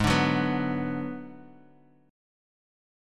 G#m7b5 Chord